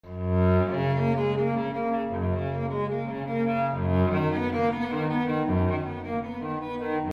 Música
El sonido musical está compuesto por ondas seno múltiplos de una frecuencia fundamental.